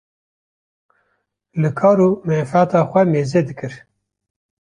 Uitgesproken als (IPA)
/meːˈzɛ/